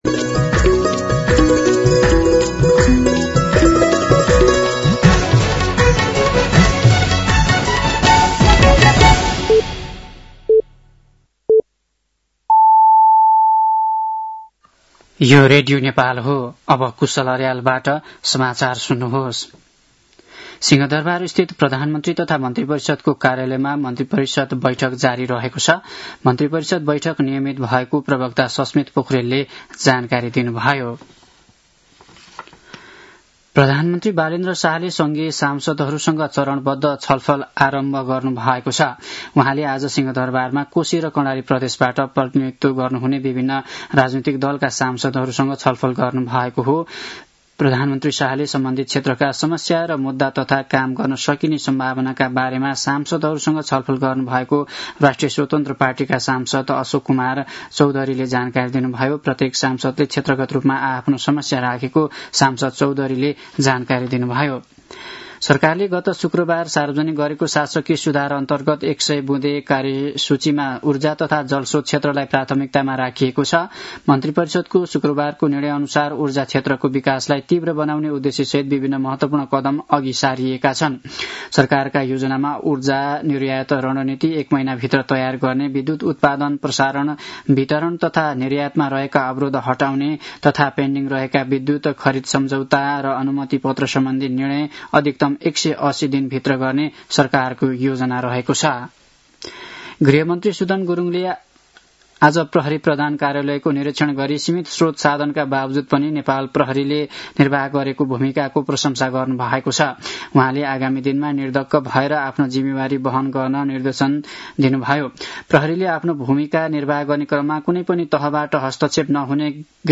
साँझ ५ बजेको नेपाली समाचार : १६ चैत , २०८२